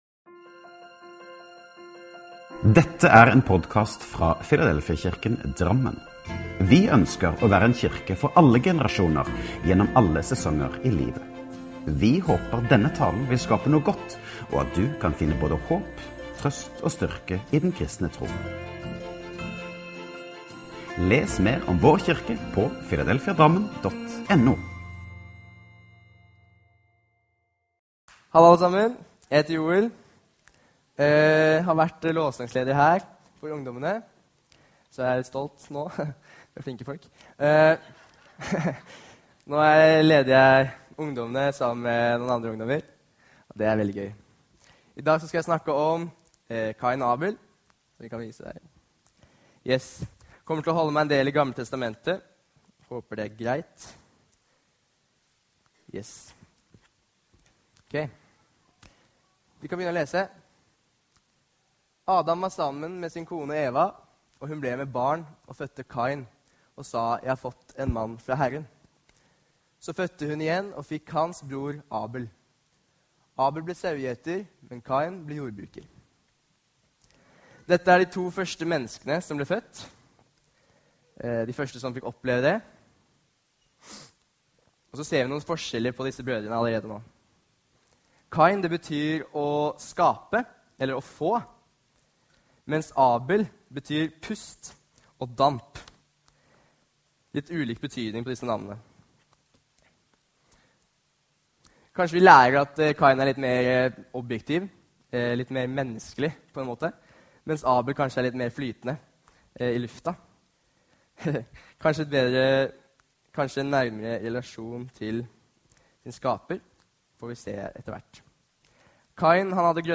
talen